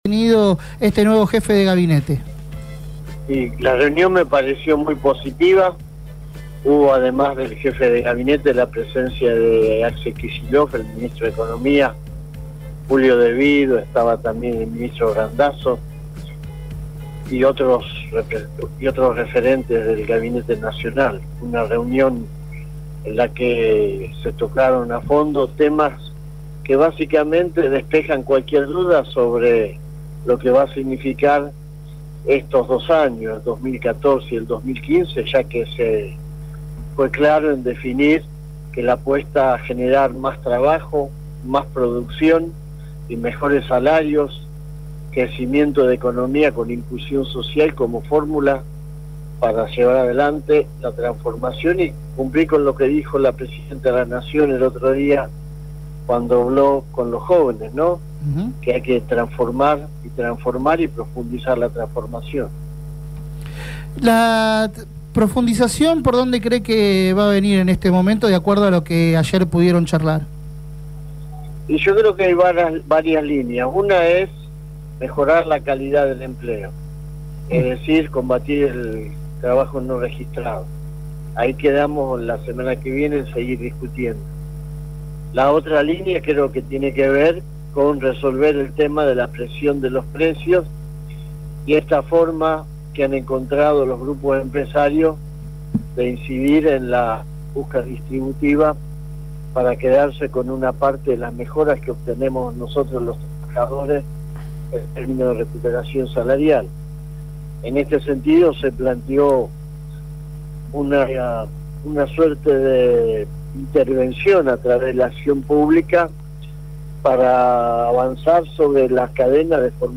En comunicación con Feos, Sucios y Malas, Yasky apuntó contra Facundo Moyano: “Me aflige verlo ahora en un papel tan opaco en su conversión a sectores más tradicionales de la derecha”.